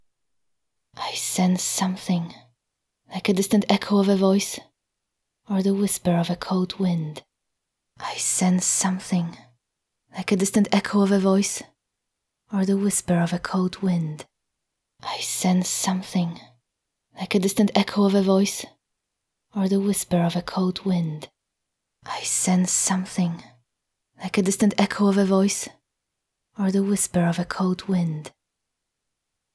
> That is, first and third plays are with HRTF, second and fourth are
> Both have coloring, but the stereo sound also sounds "wider" when HRTF
> I recorded them in Audacity and exported as flac:
HRTF stereo coloring.flac